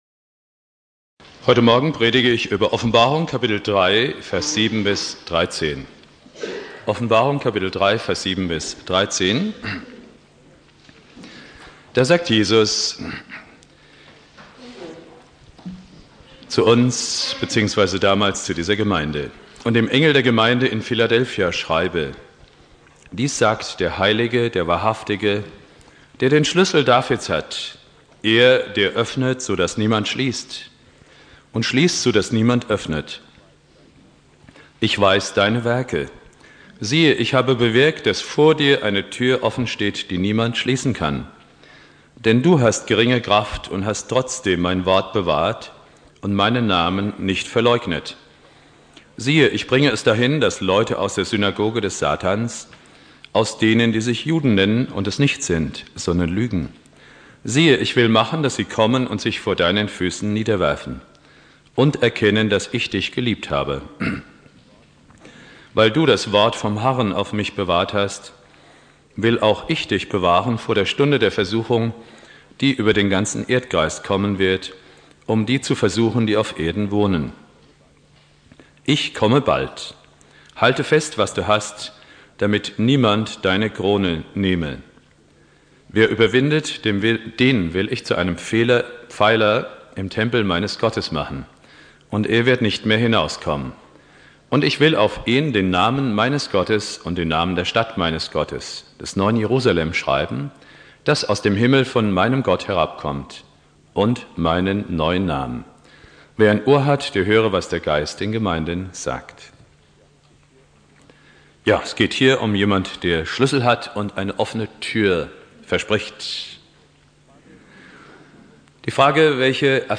Predigt
2.Advent